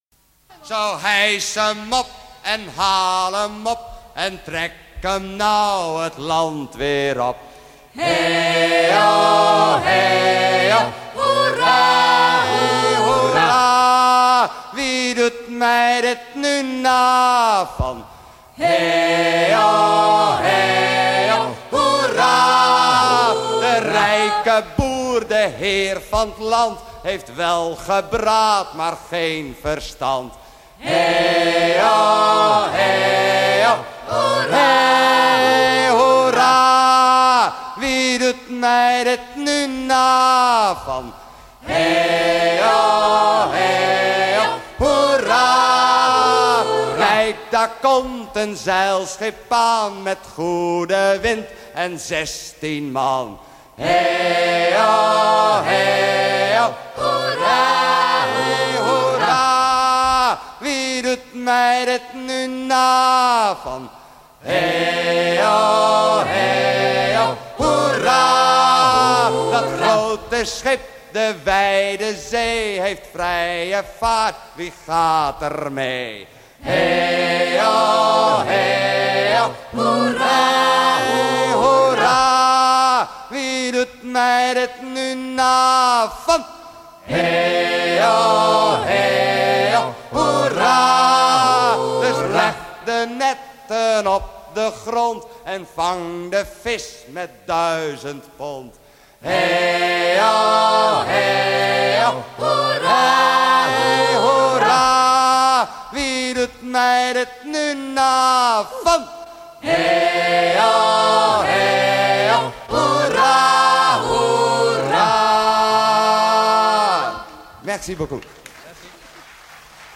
chant rythmant le travail des pêcheurs des îles de la Frise rentrant leurs filets
Pièce musicale éditée